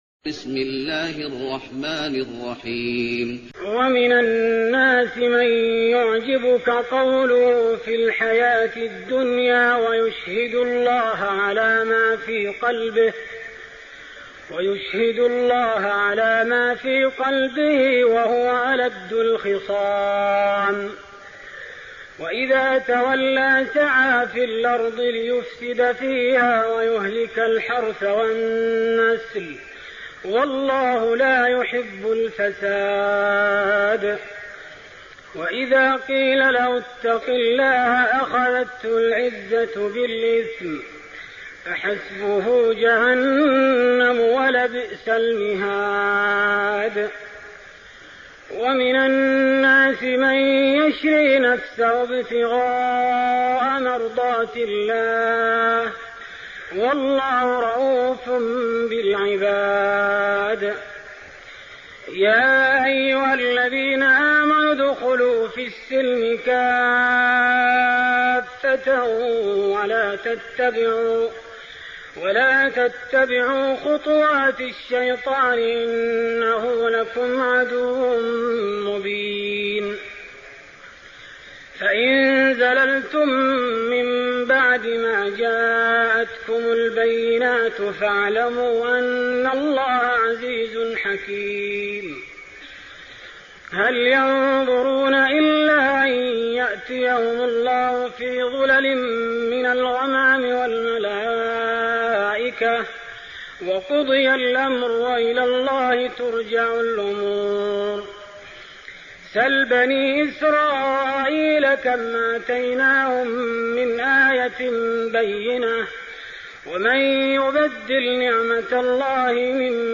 تهجد رمضان 1416هـ من سورة البقرة (204-260) Tahajjud night Ramadan 1416H from Surah Al-Baqara > تراويح الحرم النبوي عام 1416 🕌 > التراويح - تلاوات الحرمين